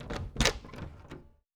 03_书店外黄昏_开门.wav